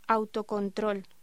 Locución: Autocontrol